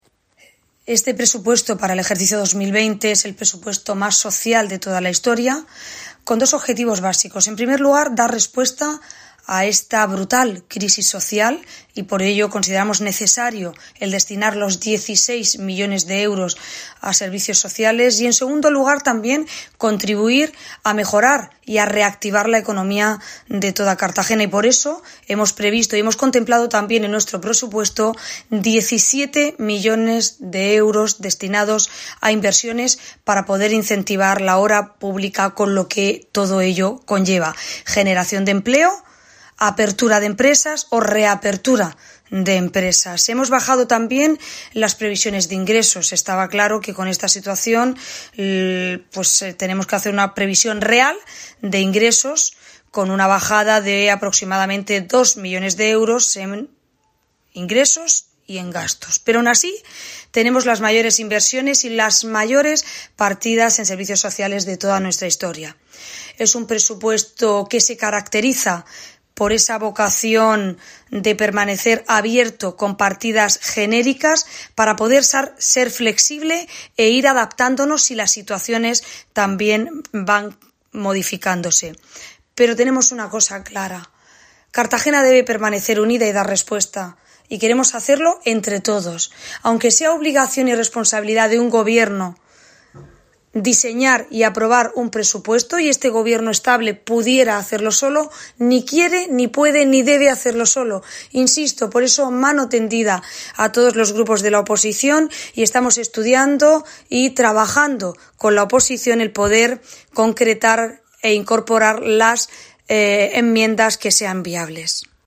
Enlace a Declaraciones de la alcaldesa sobre la negociación del presupuestos con los grupos municipales de la oposición